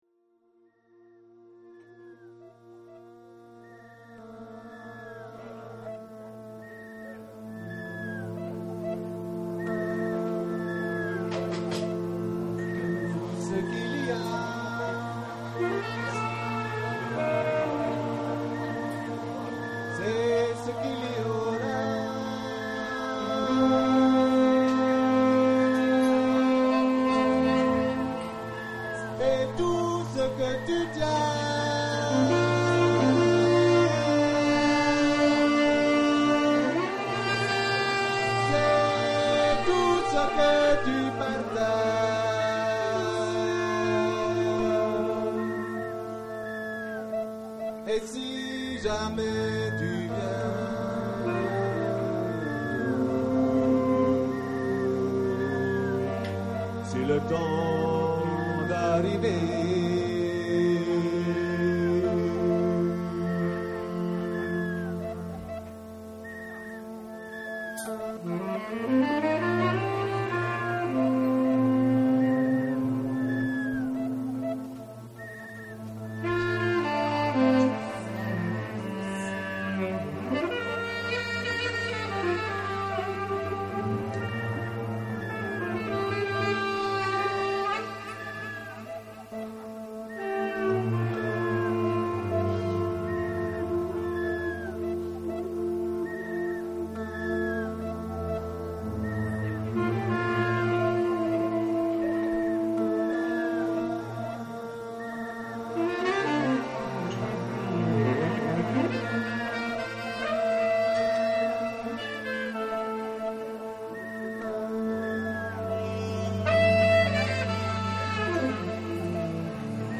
calypso, blues, loop, trip
zwischen geloopter Improvisation und akustischem Calypso.
Trumpet/Vocals
Saxophone/Clarinet
Kontrabass